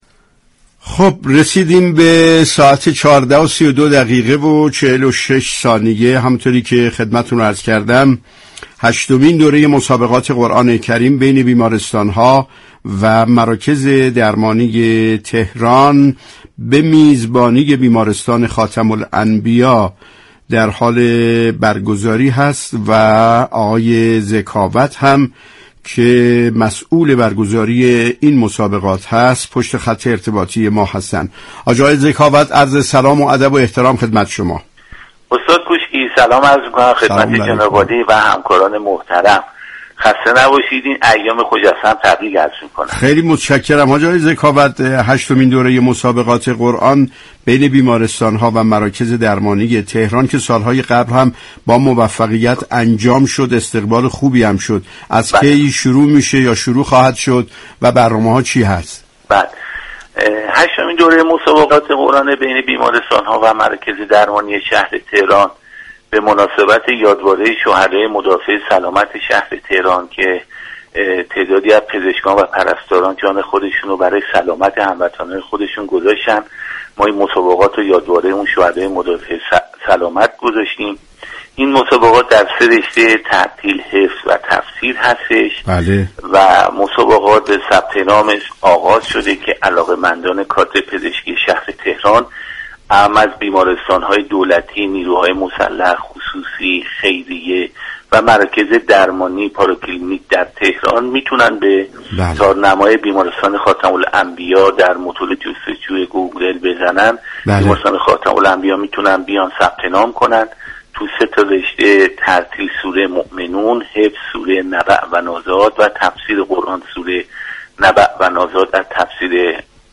در گفتگو با برنامه والعصر رادیو قرآن